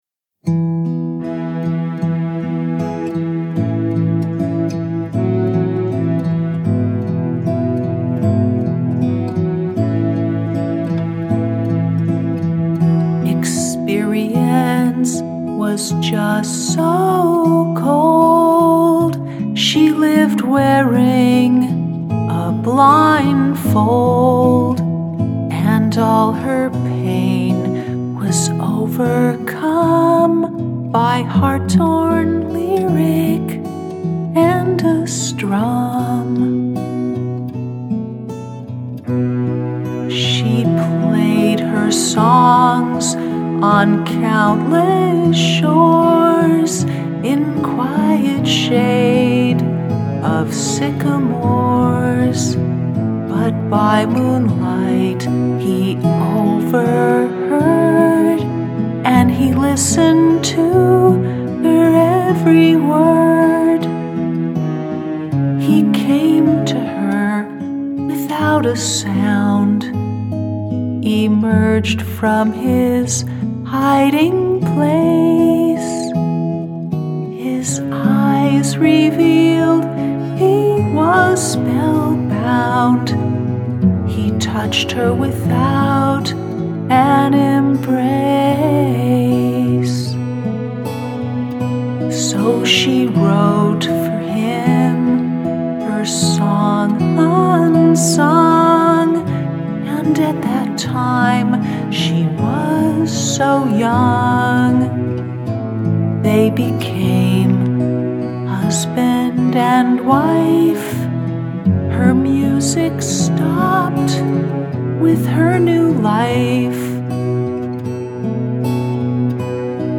It was one of my favorite songs because of the song’s pleasant chord progression. I wrote it as a simple ballad about playing my music and discovering love.